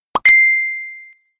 Genre: Nhạc chuông tin nhắn